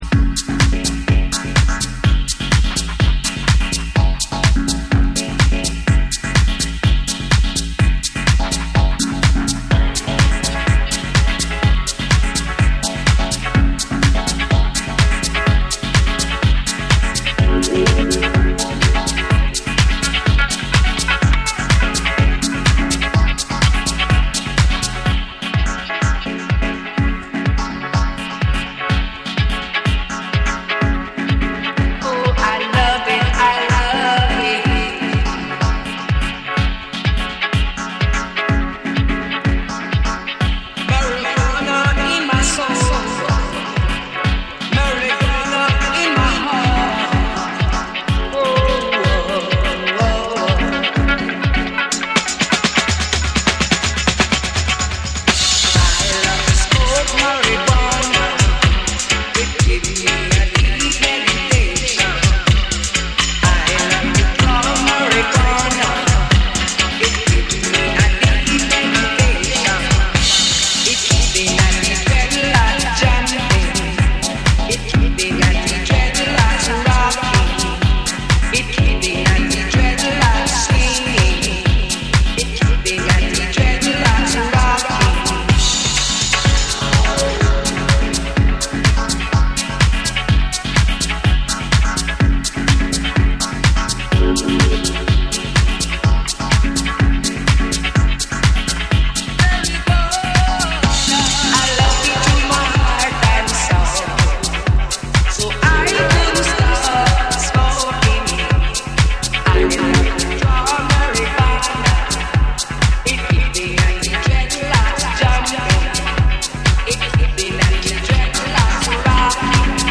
ディープな空間処理で脳と身体を揺らすダブ・テクノ
JAPANESE / TECHNO & HOUSE / REGGAE & DUB